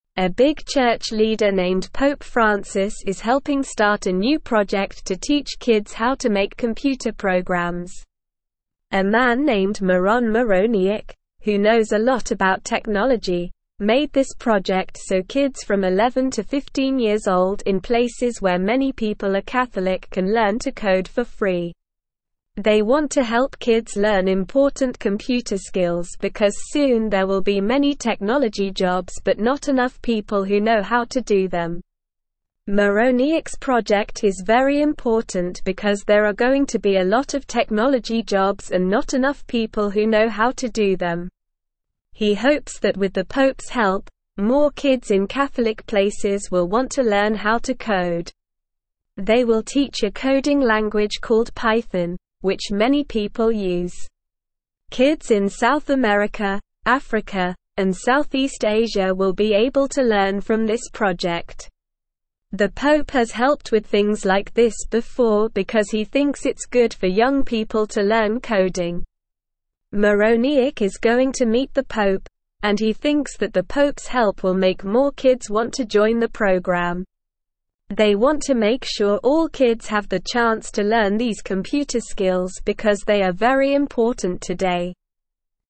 Slow
English-Newsroom-Lower-Intermediate-SLOW-Reading-Church-Leader-Supports-Free-Computer-Class-for-Kids.mp3